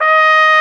Index of /90_sSampleCDs/Roland LCDP12 Solo Brass/BRS_Trumpet 5-7/BRS_Tp 7 Warm